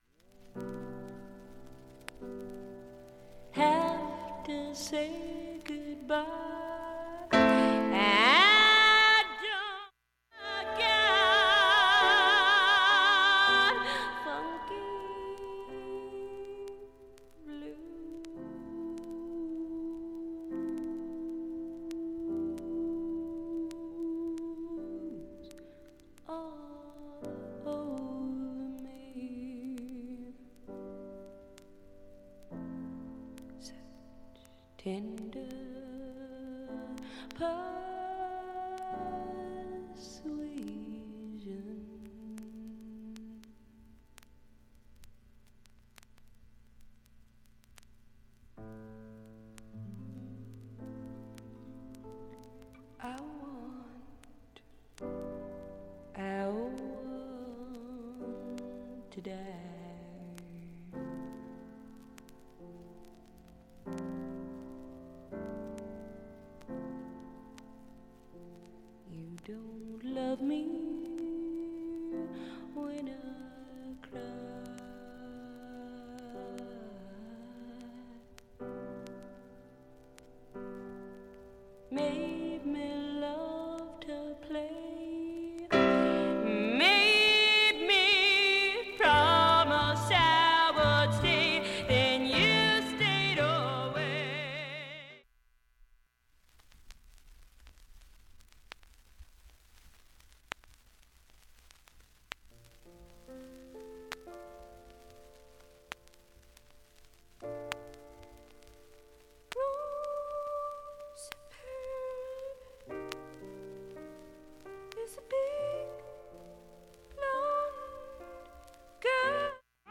静かな部でかすかなチリ出ますが
B面後半などはかなりクリアです。
普通に聴けます音質良好全曲試聴済み
かすかなプツが出ます。